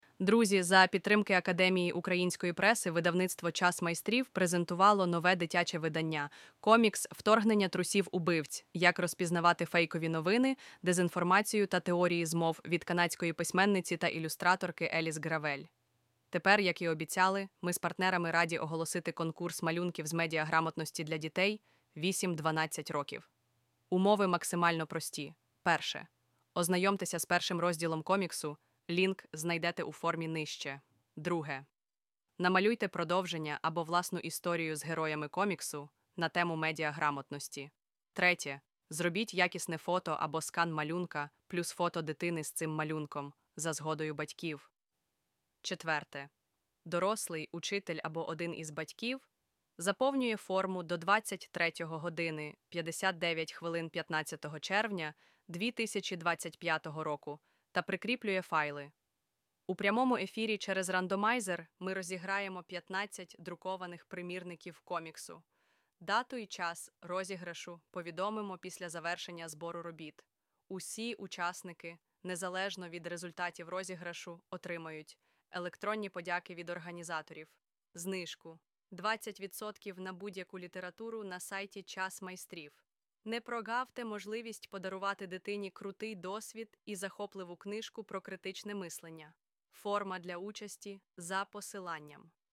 Цей аудіозапис створений LLM від elevenlabs в тестовому режимі для покращення та зрунчості сприйняття інформації.